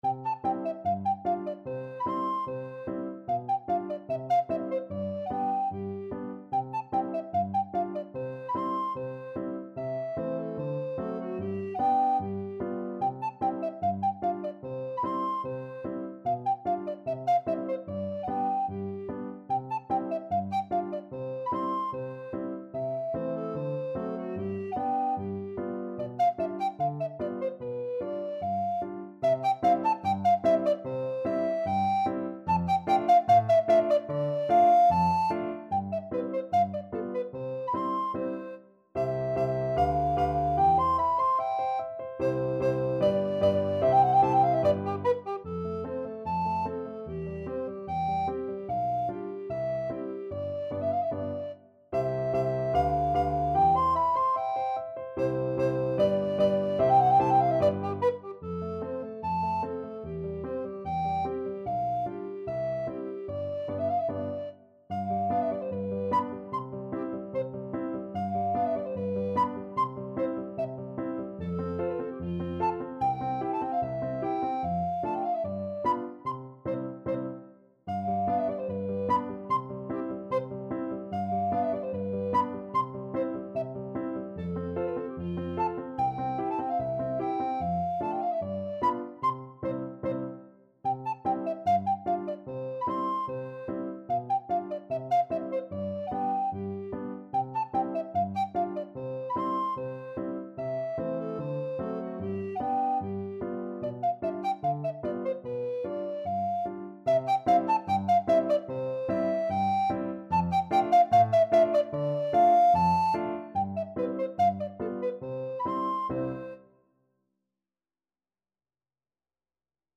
Alto Recorder
C major (Sounding Pitch) (View more C major Music for Alto Recorder )
Allegretto = 74
2/2 (View more 2/2 Music)
F5-C7